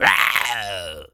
tas_devil_cartoon_12.wav